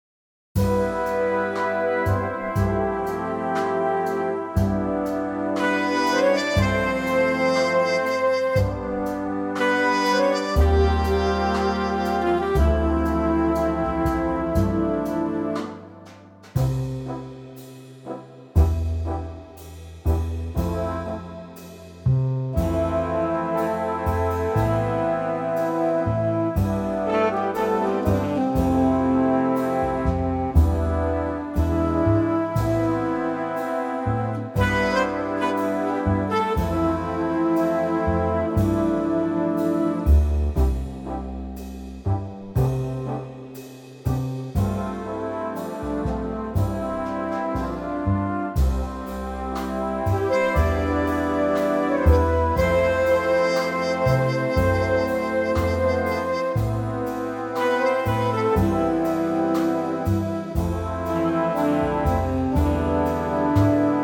key - C - vocal range - C to F
Superb mellow Big Band arrangement